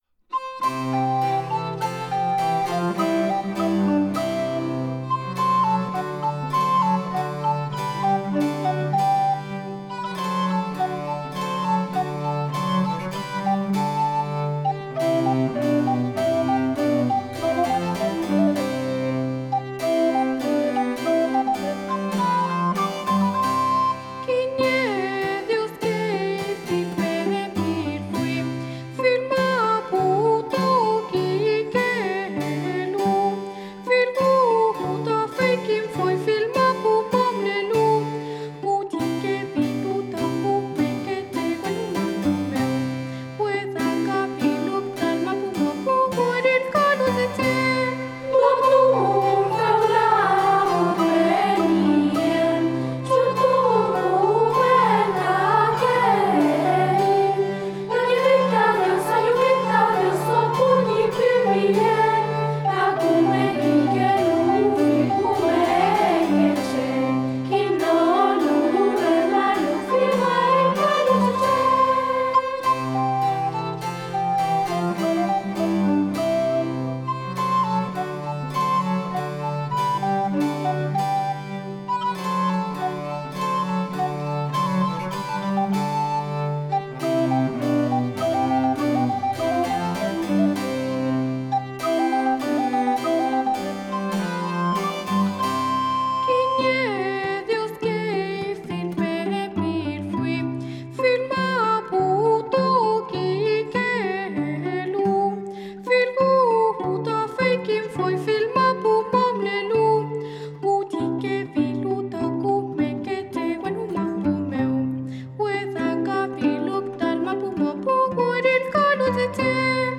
Música religiosa
Música vocal